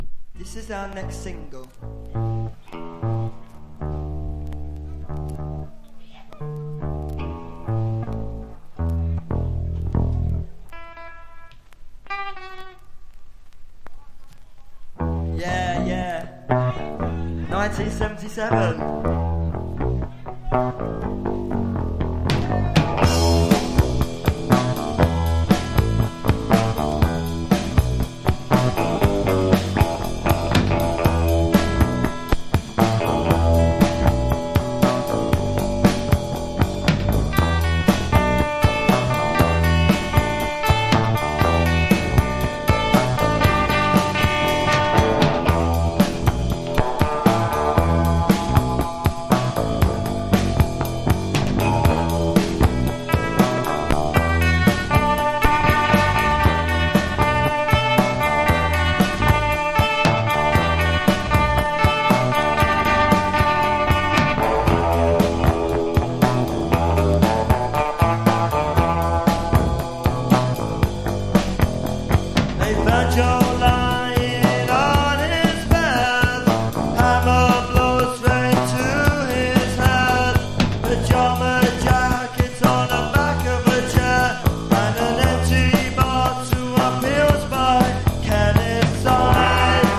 # NEW WAVE# POST PUNK# 80’s ROCK / POPS# PUNK / HARDCORE